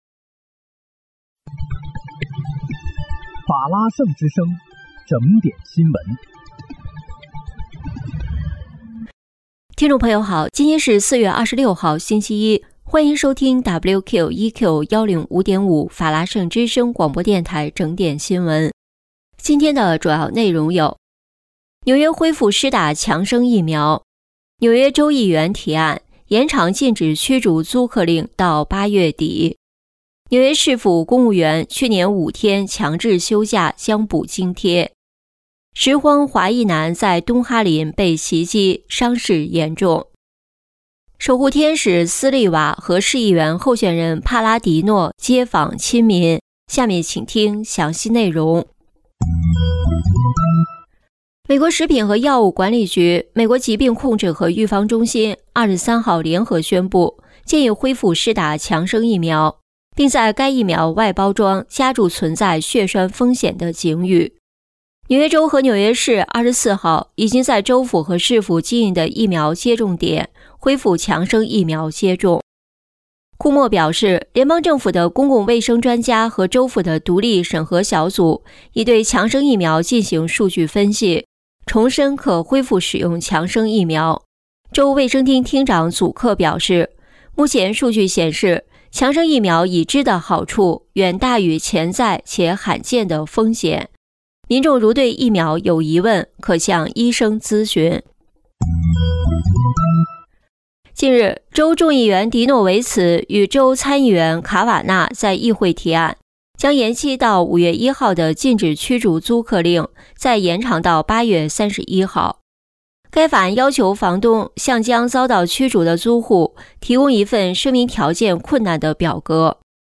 4月26日（星期一）纽约整点新闻